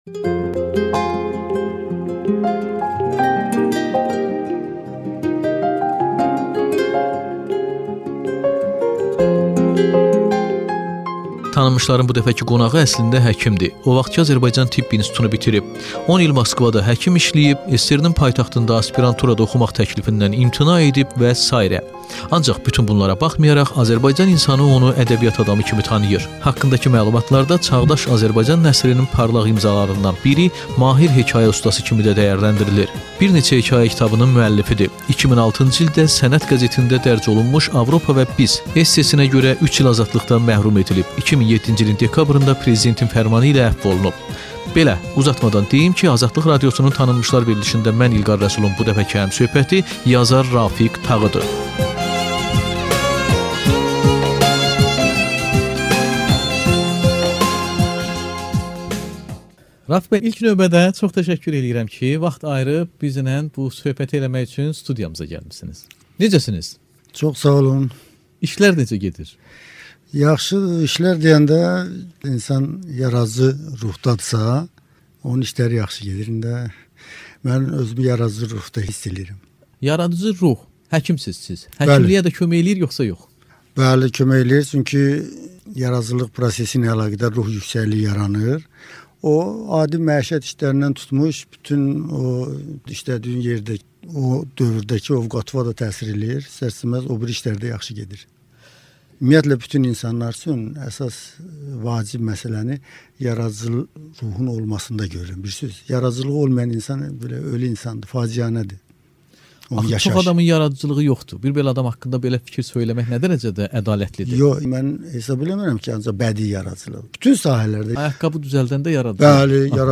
İnterview with Azeri author